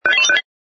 sfx_ui_react_processing01.wav